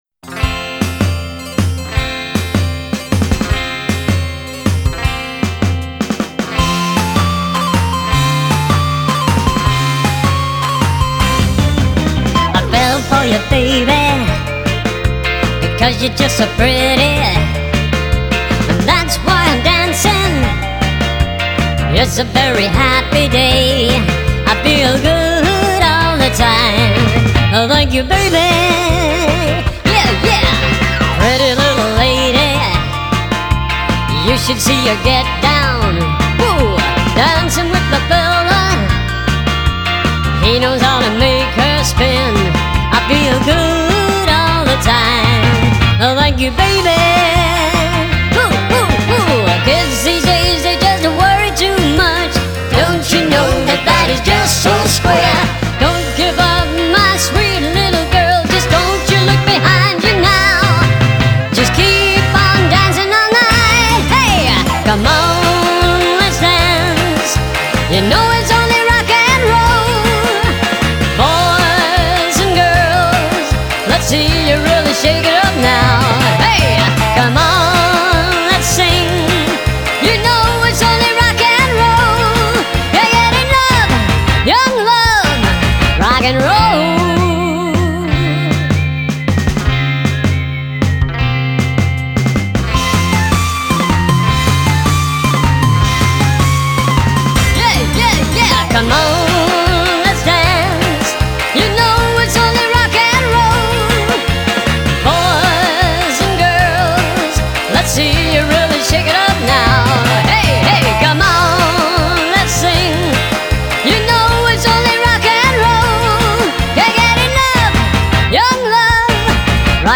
BPM156
Audio QualityPerfect (High Quality)
rock-a-billy style from the 60's